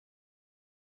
comment_voiced_se_25_secret_door.ogg